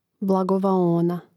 blagovaóna blagovaona